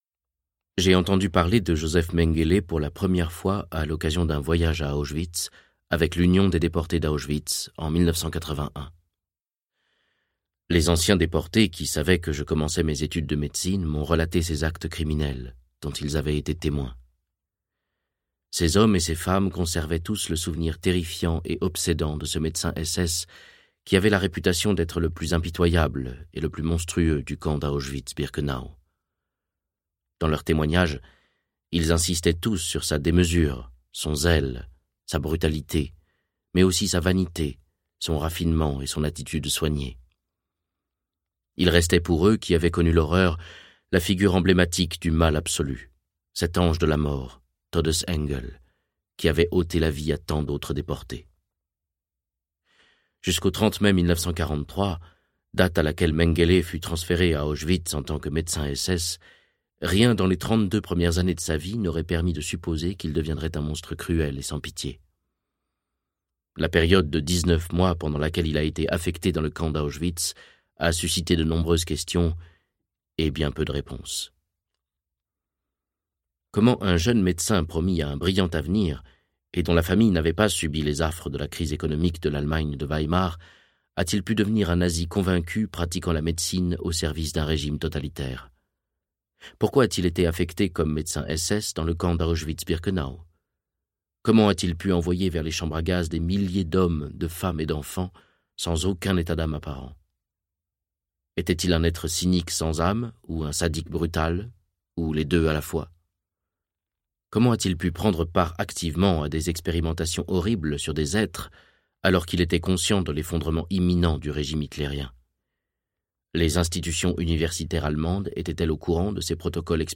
Livre audio Josef Mengele - Médecin et bourreau de Bruno Halioua | Sixtrid
Texte : Intégral